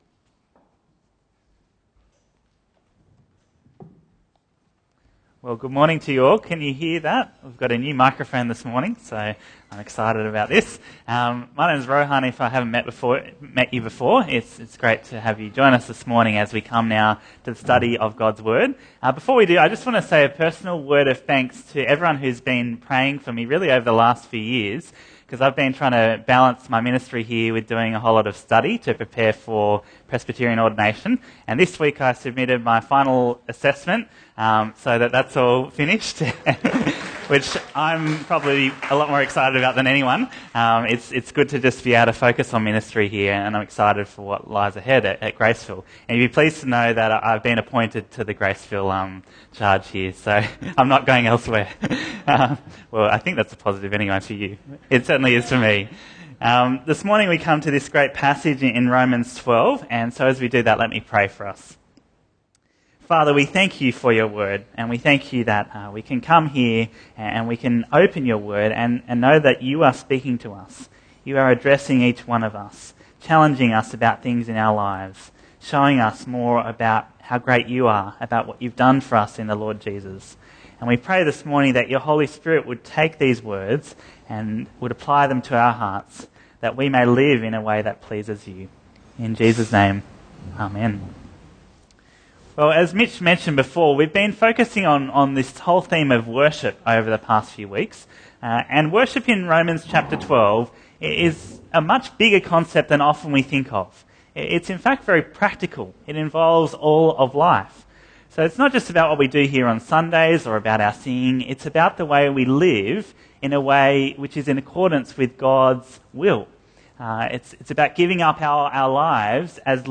Bible Talks Bible Reading: Romans 12:9-21